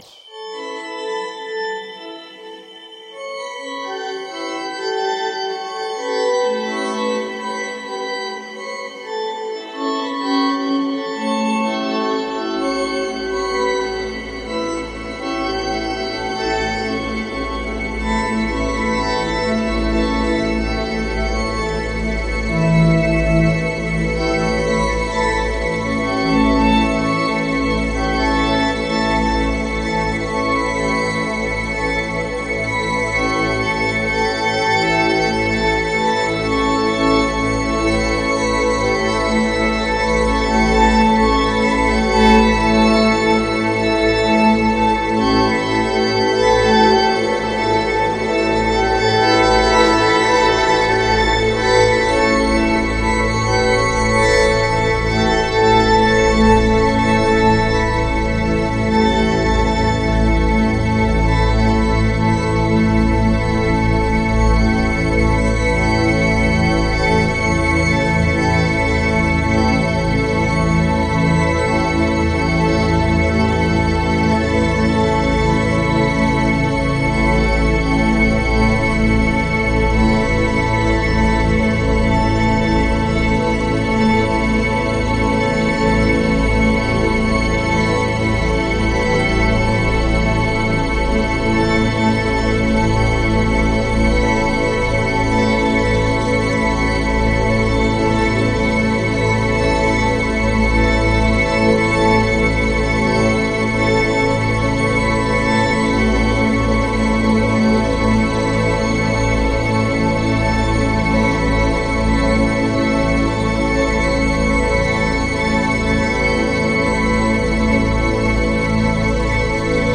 Birds in Chiang Mai, reimagined